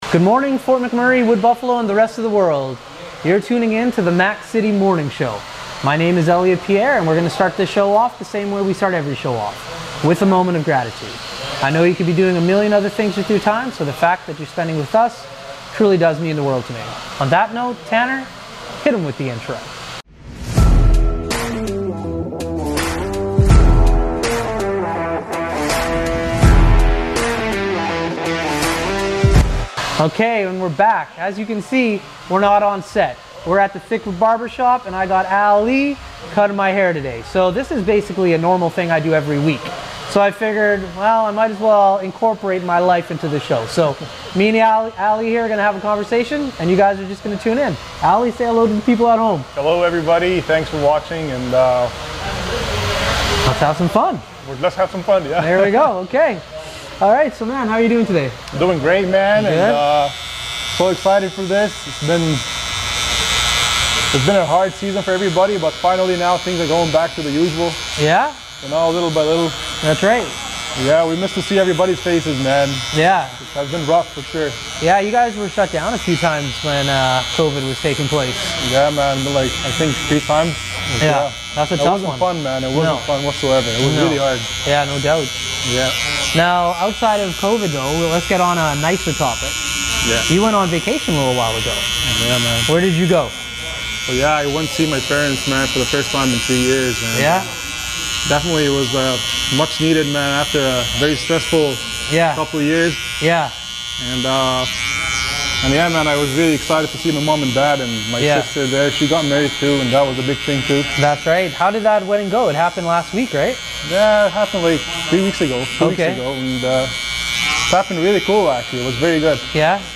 #143: On Location at Thickwood Barbershop